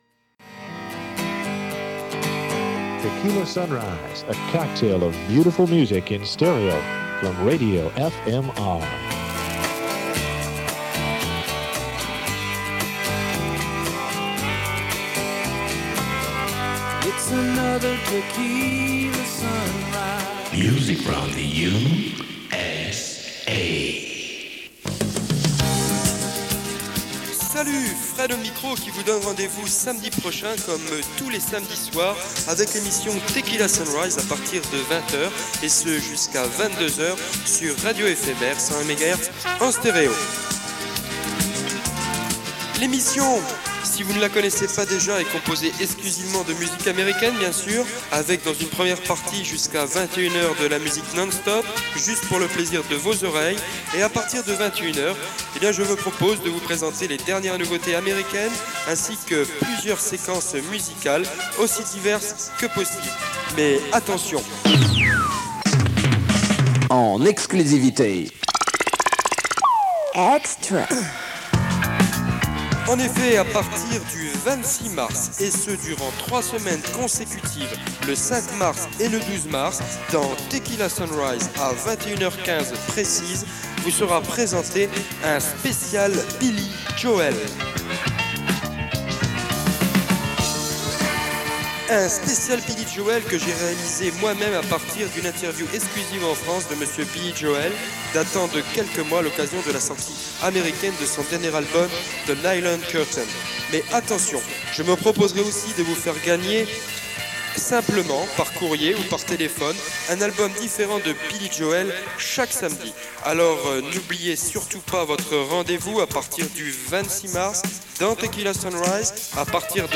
Archives sonores Radio FMR période 1982 à 198x
Bandes annonces